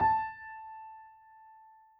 piano_069.wav